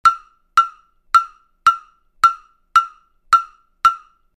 LP Jam Bell - Blue High Pitch
LP Jam Bells have a unique shape that creates high pitched sounds. They have little sustain which makes them perfect for playing accents.
These are the smallest and highest pitched bells that LP offers.
Videos and Sound Clips LP LP1231 Sound Sample 1 Customer Reviews Write a review Cool jam bell Comments: Nice rich full sound. This thing really projects.